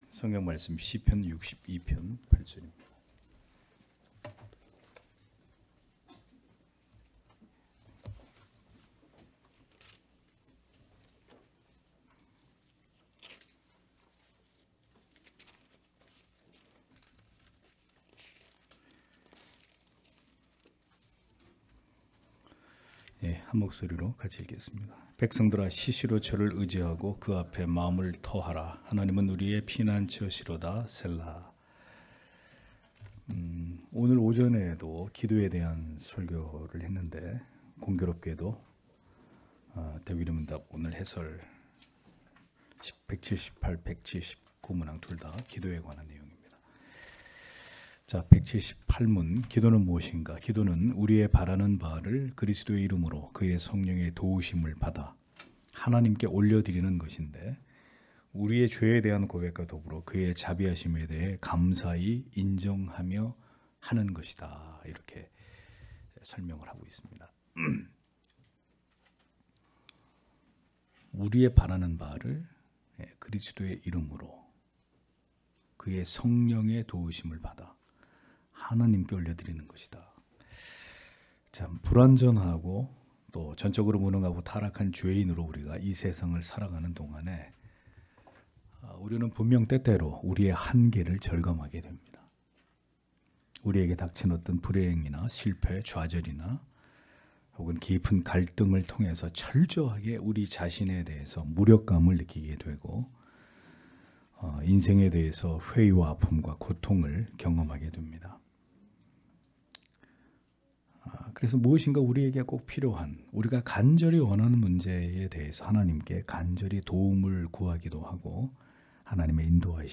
주일오후예배 - [웨스트민스터 대요리문답 해설 178-179] 178문) 기도란 무엇인가? 179문) 우리는 하나님께만 기도할 것인가?(시편 62편 8절)
* 설교 파일을 다운 받으시려면 아래 설교 제목을 클릭해서 다운 받으시면 됩니다.